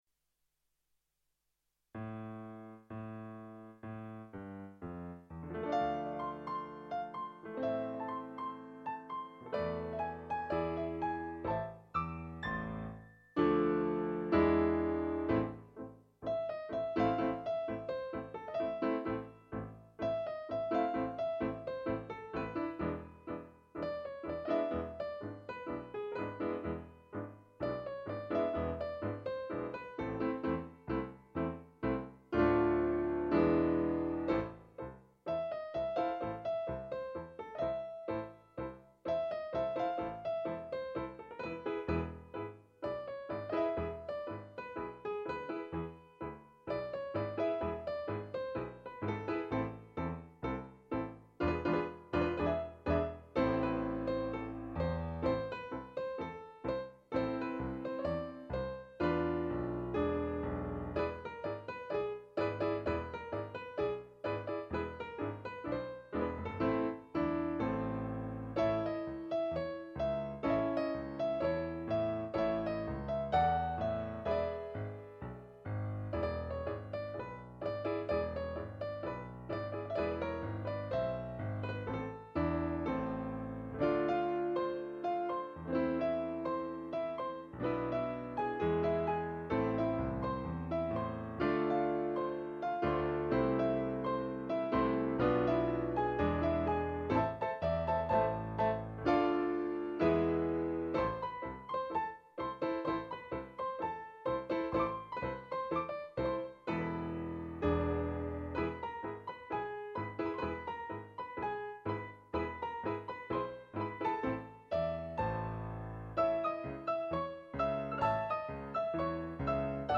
TANGO
A MON EPOQUE (Tango Piano) C'EST SI LOINTAIN (Tango Piano) C'ETAIT HIER (Tango Piano) DE MON TEMPS (Tango Piano) L'OUBLI (Tango Piano) TANT DE SOUVENIRS(Tango Piano) UNA FELICITA(Tango Piano)